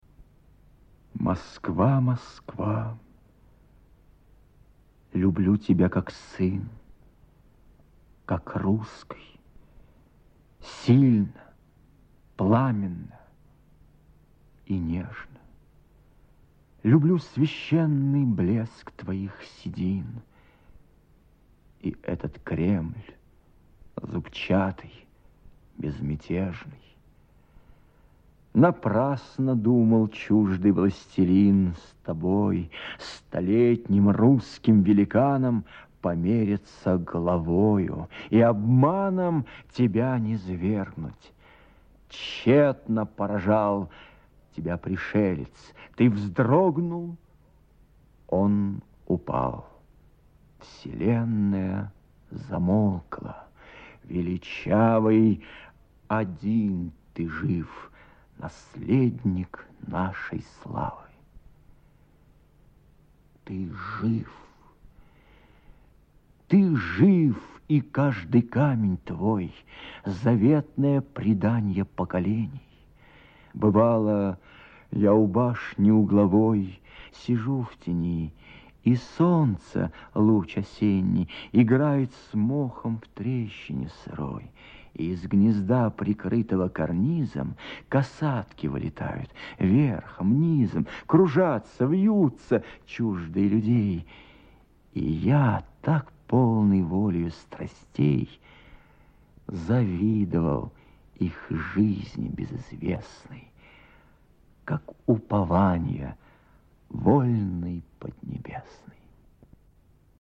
Д. Пятистопный ямб.
Прослушивание отрывка «Москва, Москва!.. Люблю тебя, как сын...» в исполнении Э. Марцевича:
- Москва, Москва!.. – Восклицание задумчивое, мягкое, протяжное, с любовью и теплотой в голосе.
- Люблю тебя как сын, – Упор на слово сын, задумчивость в голосе.
- Как русский, – сильно, пламенно и нежно! – Подчеркнут смысл каждого слова: русский – с достоинством, сильно – c силой в голосе, пламенно – страстно, нежно – нежно.